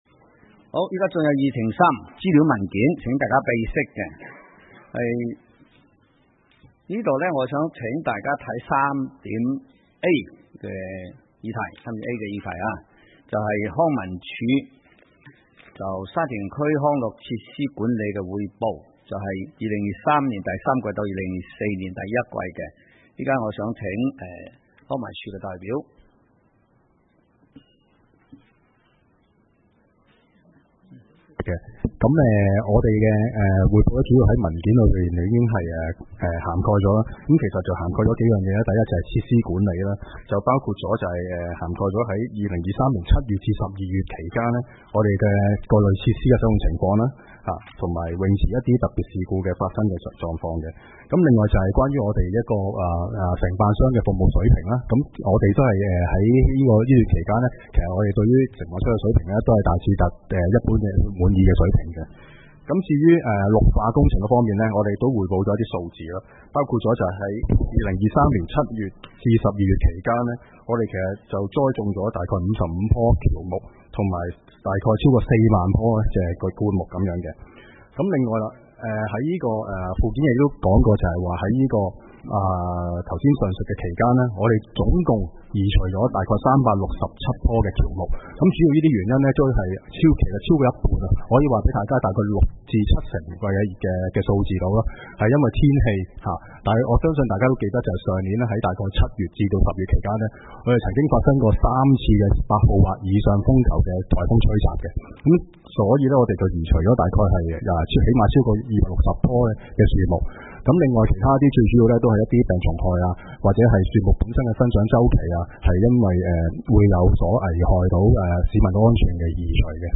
會議的錄音記錄